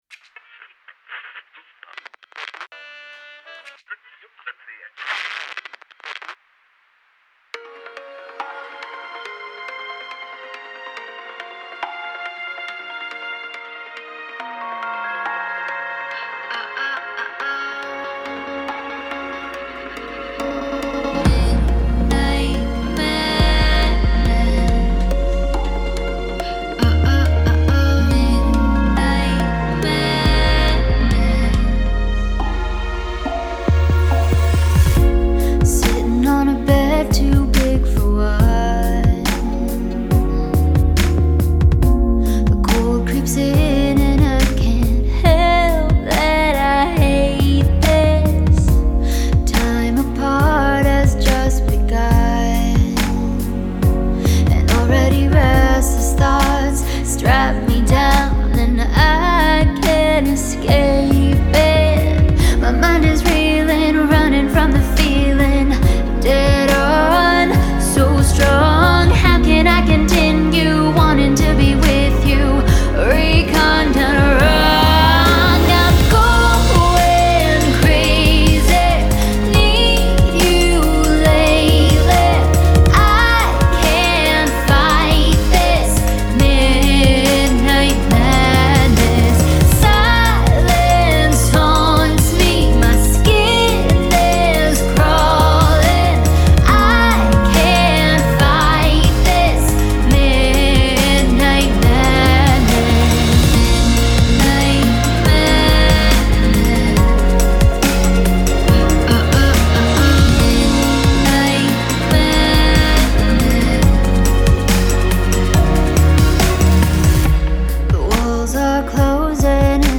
Alt-Rhythm-Pop-Funk